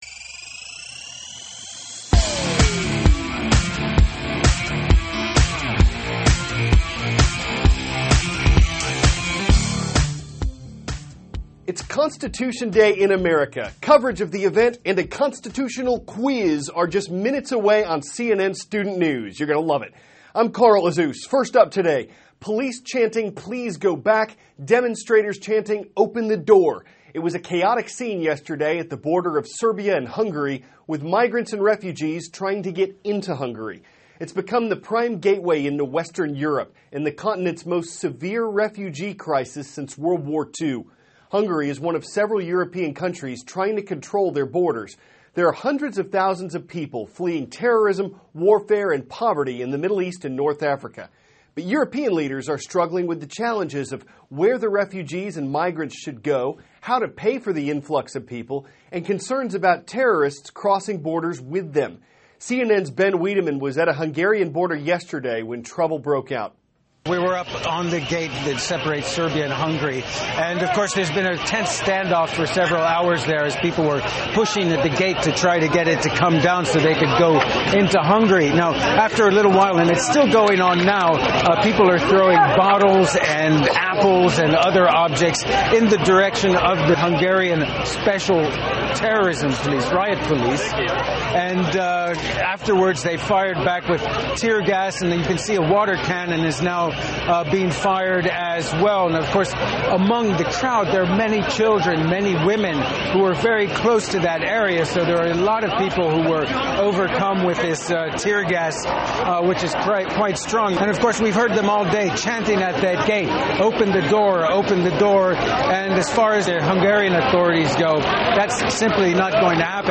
(cnn Student News) -- September 17, 2014 Constitution Day In America; Refugees Try To Push Their Way Into Hungary From Syria; Today`s Shoutout. Aired 4-4:10a ET THIS IS A RUSH TRANSCRIPT.